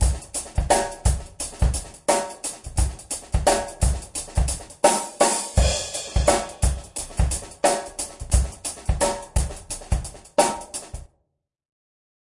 8小节的Roseanna节拍，有一些EQ和压缩。